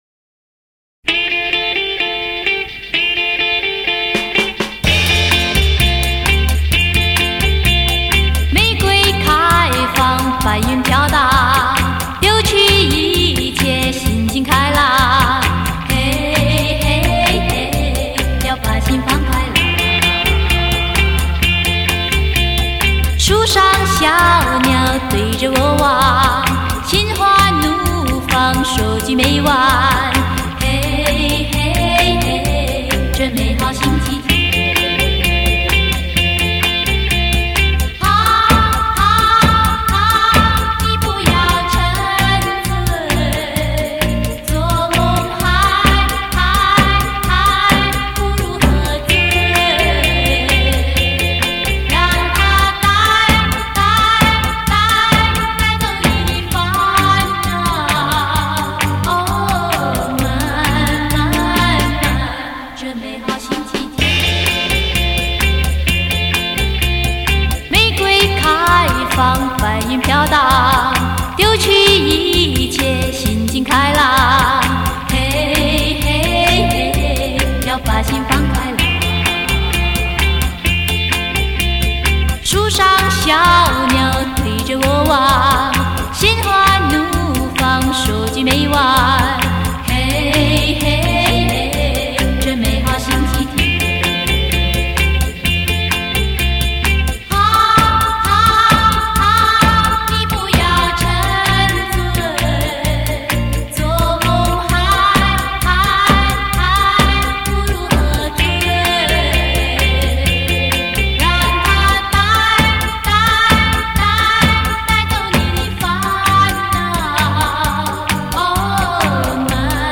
数码调音录制